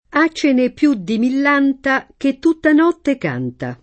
#©©ene pL2 ddi mill#nta, ke tt2tta n0tte k#nta] (Boccaccio) — sim. il cogn.